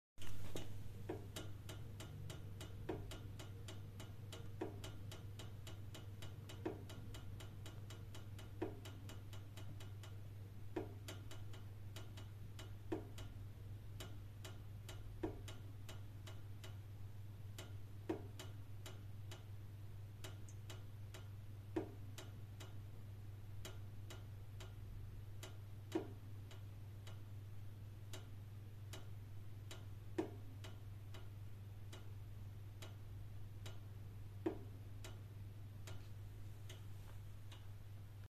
Bruits de claquements dans certains radiateurs
radiateur 2 01.mp3 lorsque l'eau chaude arrive dedans.
Ce bruit ressemble beaucoup à la dilatation de tubes emprisonnés dans la traversée de murs, et non équipés de fourreau, voire trop bridés dans les supports qui empêchent une dilatation normale.
radiateur-2-01.mp3